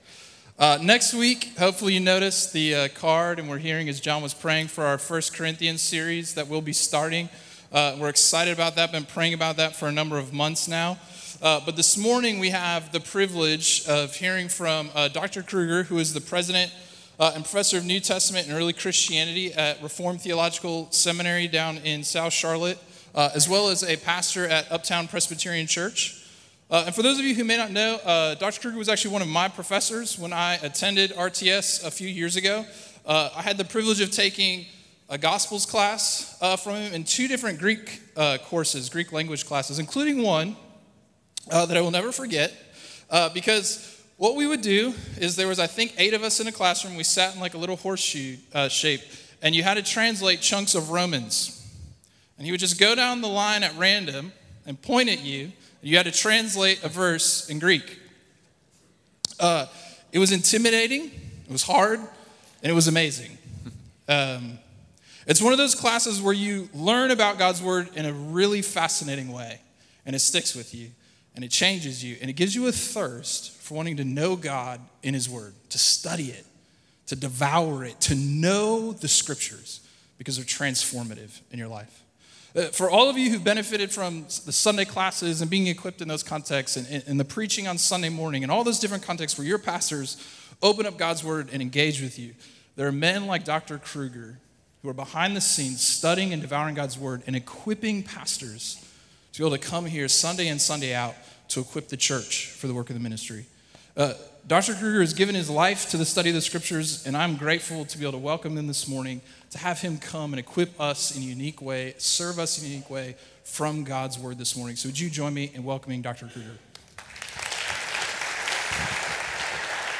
Crossway Community Church